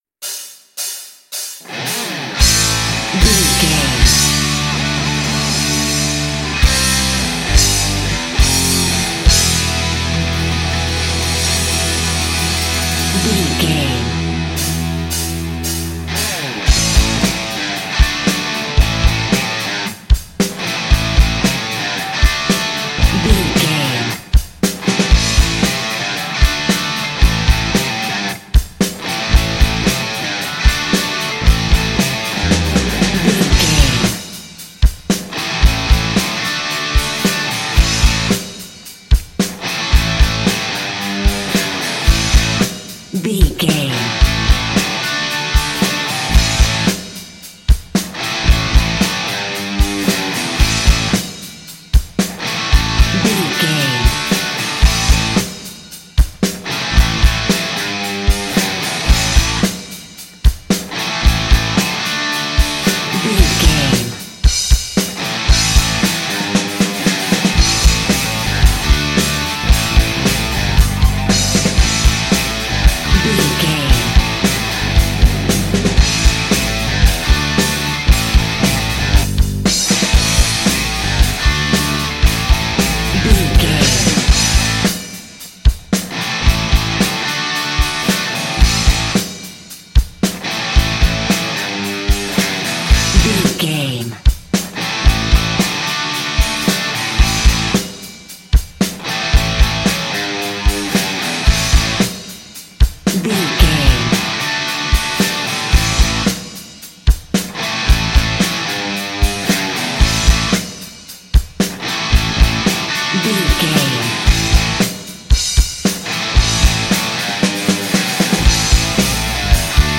Ionian/Major
DOES THIS CLIP CONTAINS LYRICS OR HUMAN VOICE?
drums
electric guitar
lead guitar
bass
aggressive
energetic
intense
powerful
nu metal
alternative metal